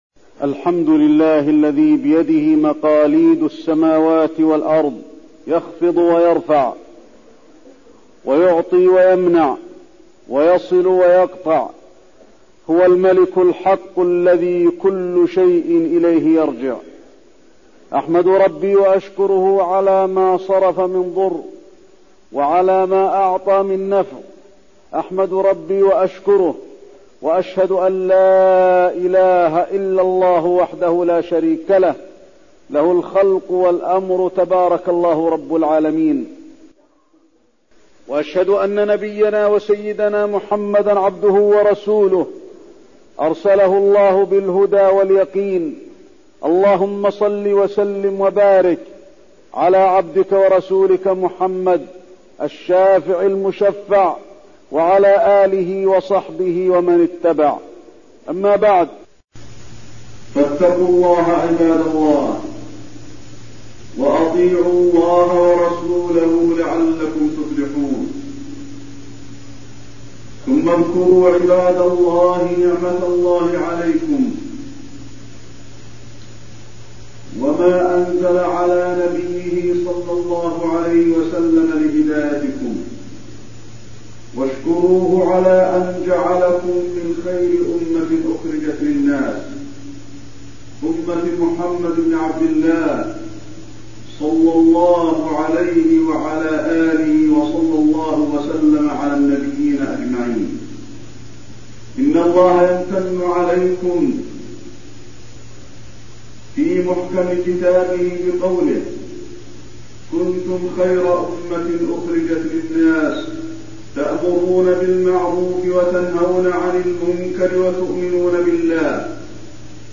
تاريخ النشر ٥ ربيع الأول ١٤٠٧ هـ المكان: المسجد النبوي الشيخ: فضيلة الشيخ د. علي بن عبدالرحمن الحذيفي فضيلة الشيخ د. علي بن عبدالرحمن الحذيفي الأمر بالمعروف والنهي عن المنكر